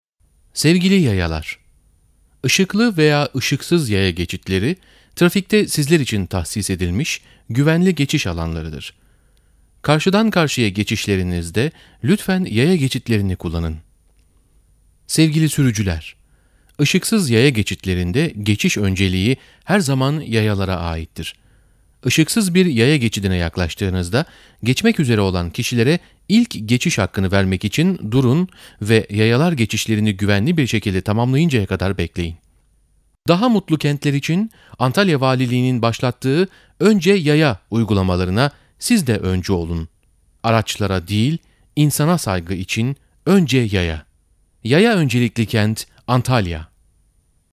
Önce Yaya Kamu Spotu (Radyo-Erkek)